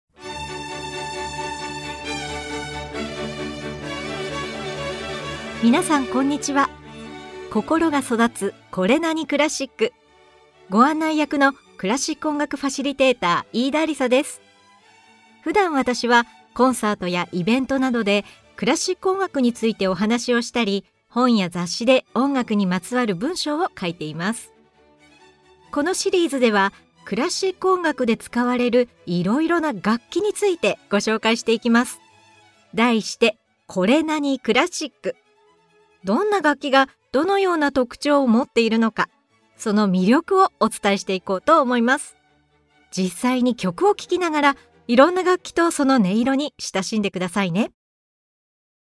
このシリーズでは、どんな楽器がどのような特徴をもっているのか、その魅力をお伝えしていきます。実際に曲を聴きながら、いろんな楽器とその音色に親しんでください。
18世紀の音楽界に現れた天才モーツァルト、ウィーンの音楽家シューベルト、ピアニストで作曲家のリストなどのピアノ楽曲を紹介しながら、ピアノのさまざまな音色とその魅力を紹介します！